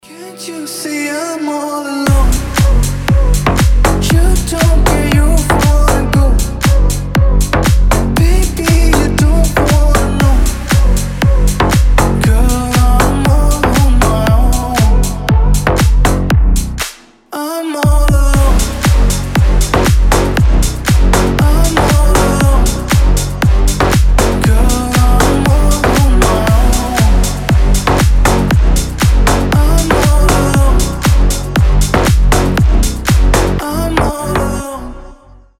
• Качество: 320, Stereo
мужской голос
басы
slap house
Вайбовая музыка